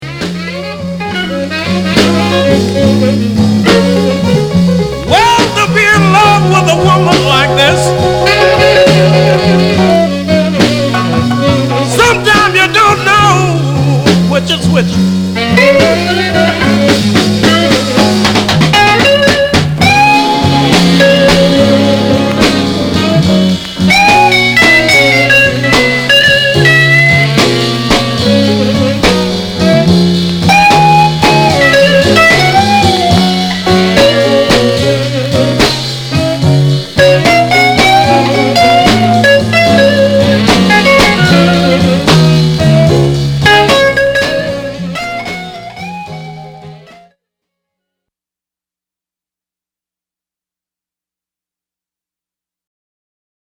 category Blues